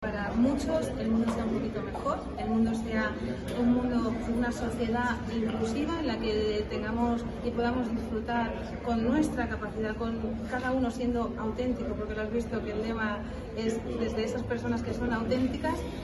al finalizar el acto